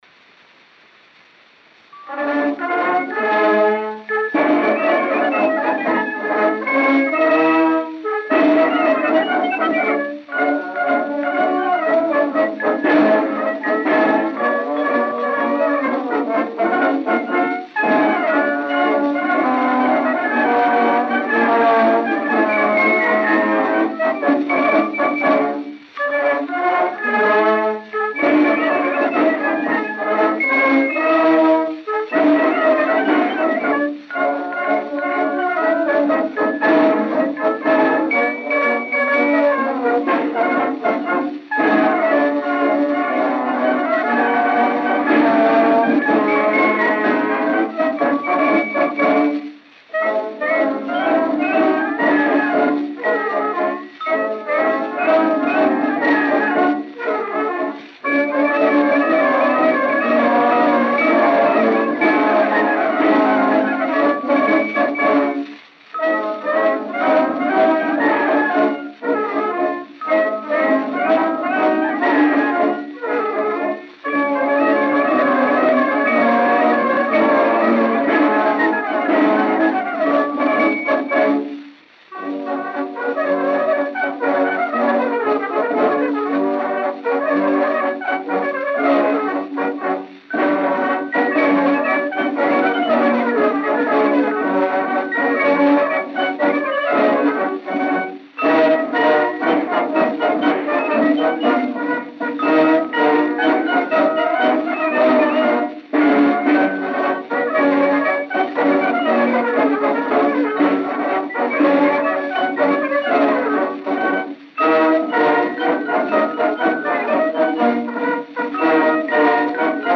Disco de 78 rotações, também chamado "78 rpm", gravado em apenas um dos lados e com rótulo "rosa".
A performance da música foi executada por "Gabetti".